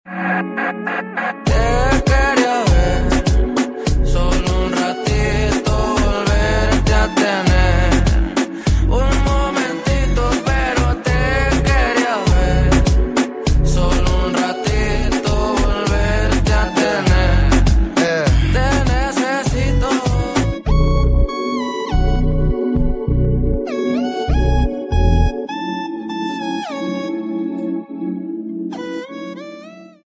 8D🎧🆙
#3DAudio